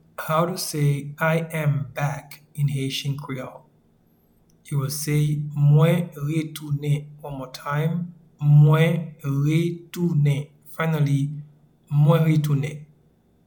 Pronunciation and Transcript:
I-am-back-in-Haitian-Creole-Mwen-retounen.mp3